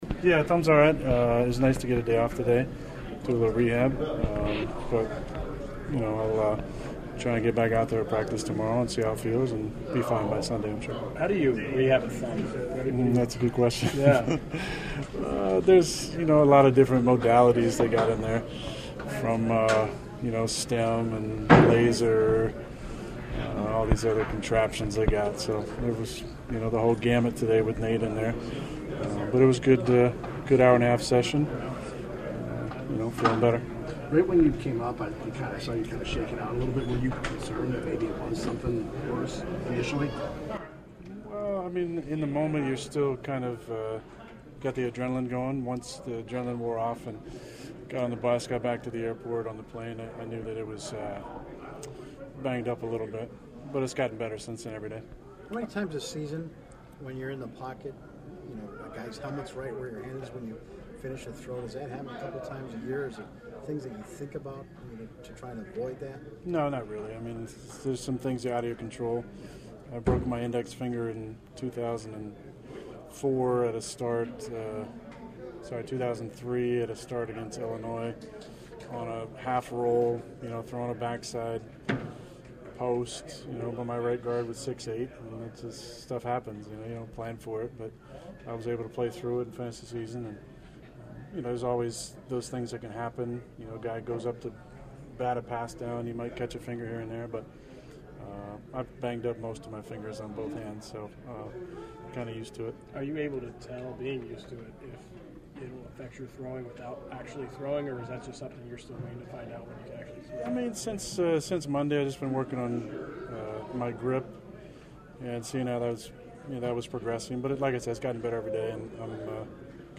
At his weekly get together with reporters, first question was obvious, “how’s the thumb?”